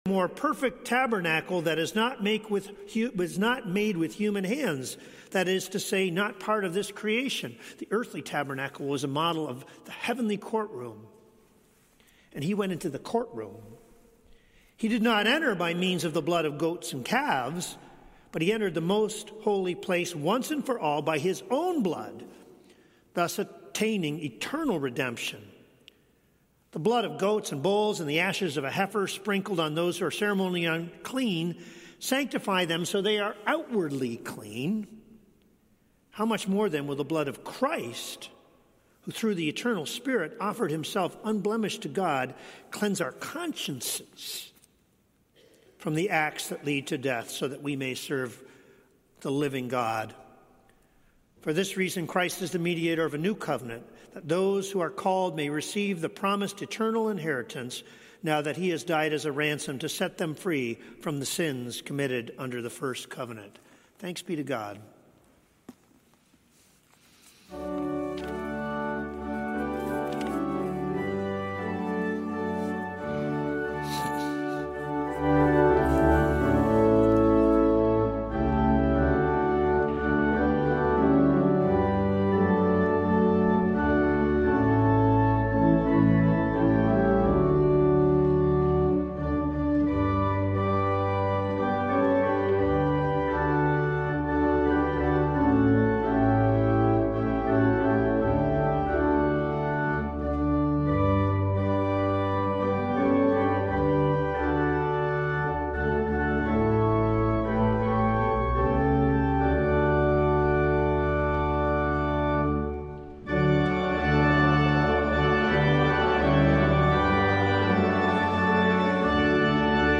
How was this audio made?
LIVE Evening Worship Service - Jesus, Our High Priest